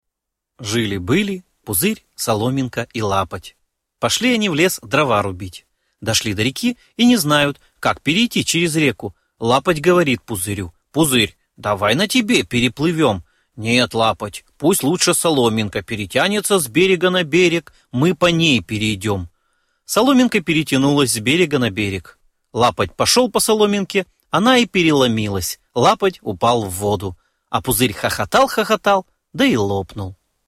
Начитка и подача текста аудио сказки